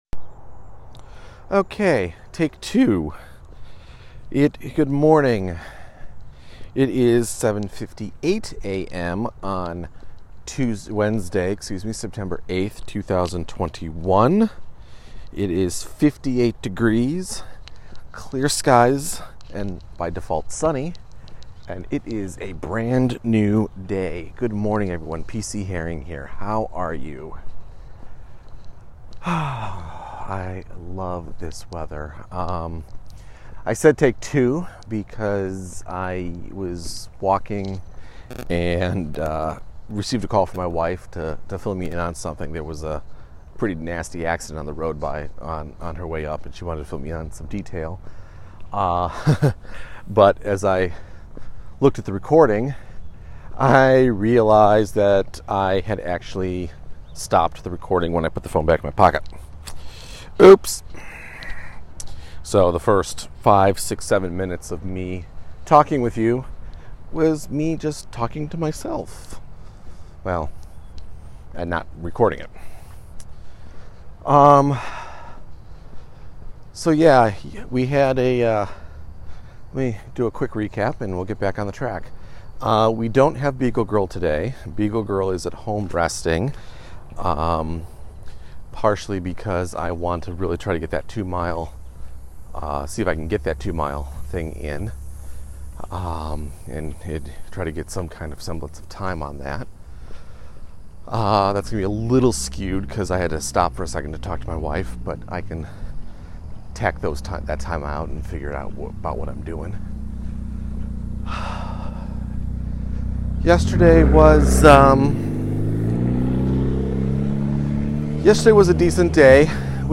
In what turns out to be a very long walk and talk, I share my thoughts on the Snyder Cut of Justice league, and then geek out way too hard on the epilogue and what could have been. It’s a spoiler filled discussion.